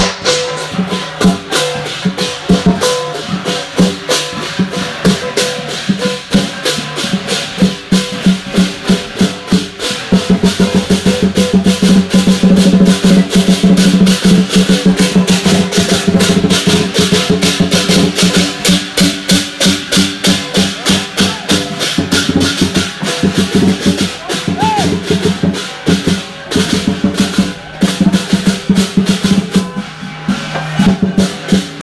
It was loud, hot and intense and the group came back to Hong Kong with new memories in tow that were put to use in the next few days.
Chinese-Sounds-Opera-Drums.m4a